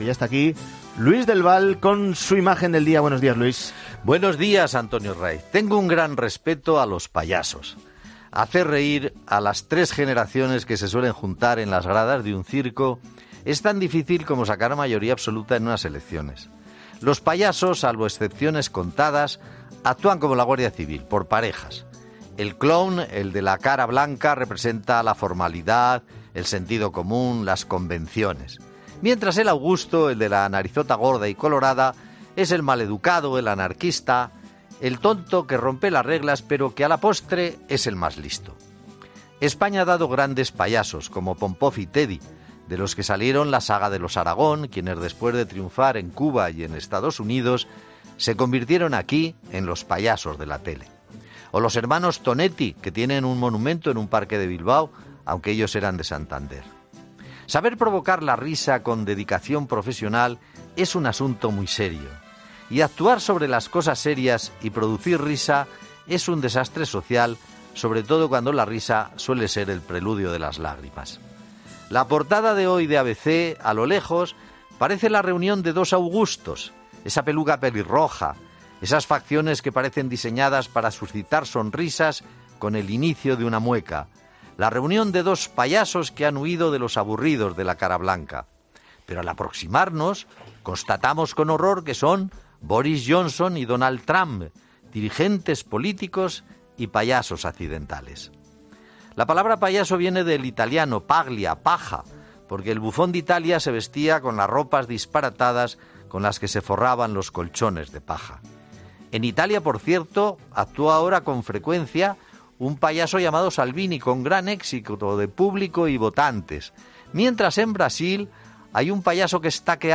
El periodista analiza en 'Herrera en COPE' los últimos acontecimientos de la política internacional